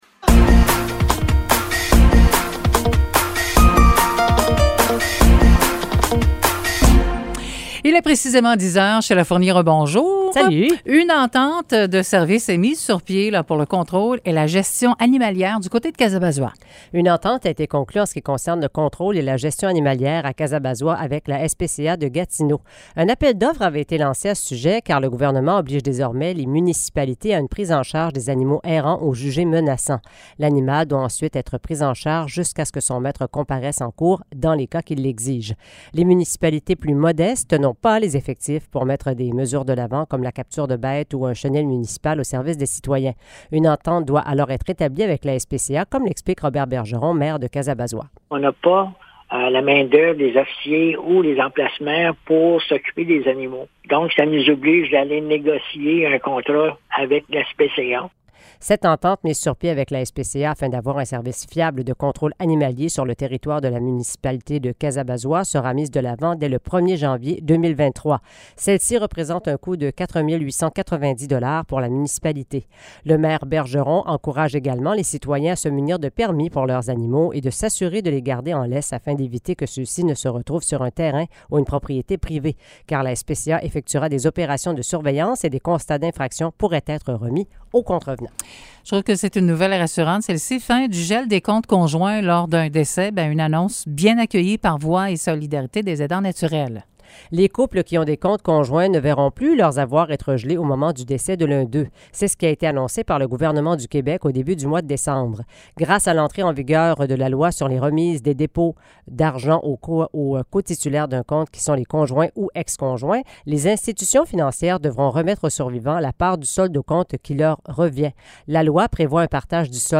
Nouvelles locales - 13 décembre 2022 - 10 h